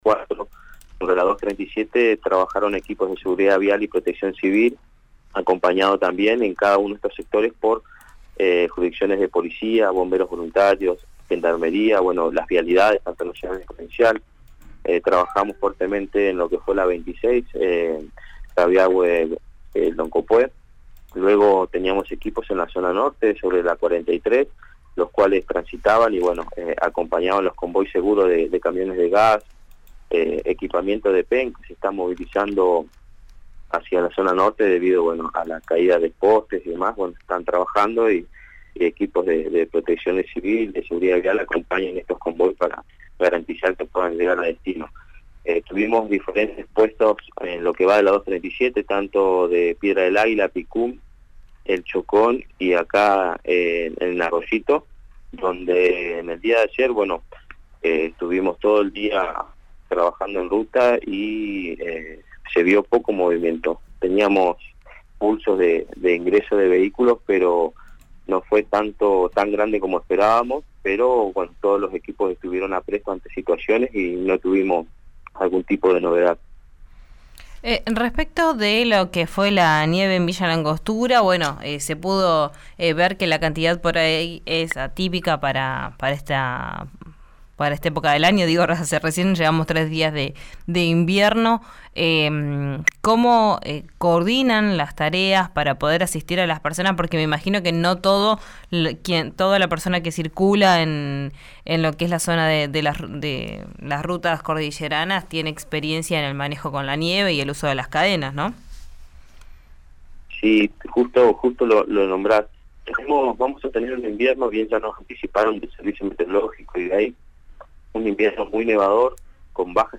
Escuchá a Carlos Cruz en RÍO NEGRO RADIO